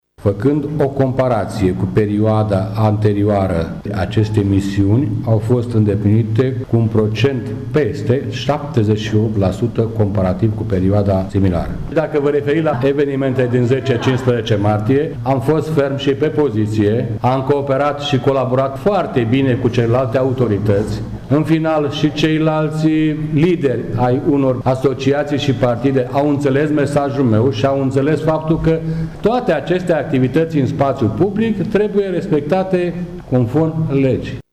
Colonelul Liviu Coman a prezentat, astăzi, într-o conferință de presă, bilanțul activității sale la comanda Inspectoratului de Jandarmi Județean ”Colonel Sabin Motora”.